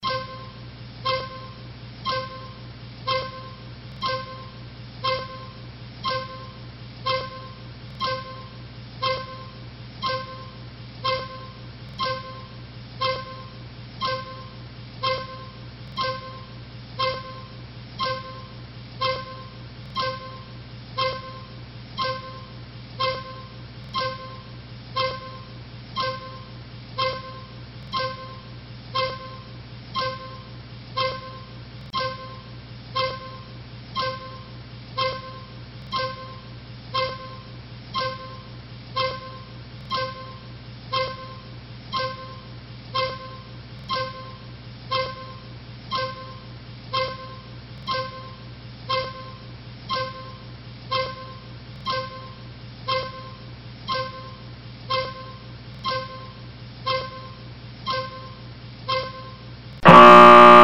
60 Second ticker with buzzer